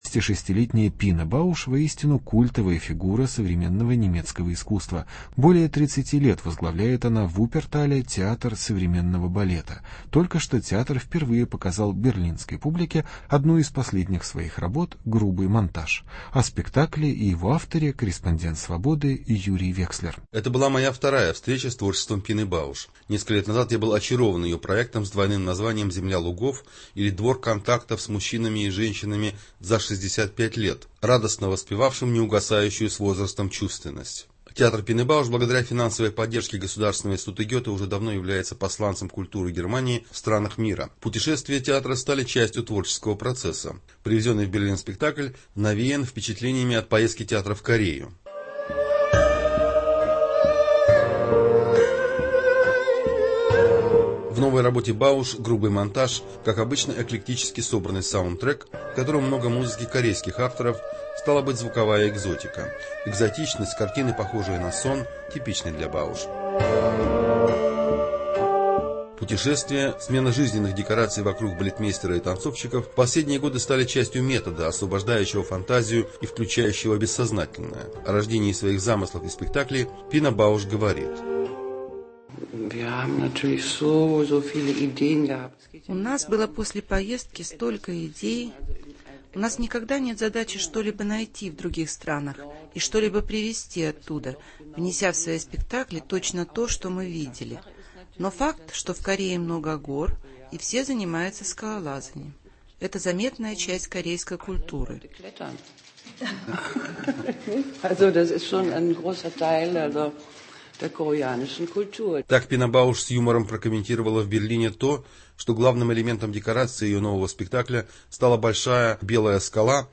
Интервью с хореографом Пиной Бауш.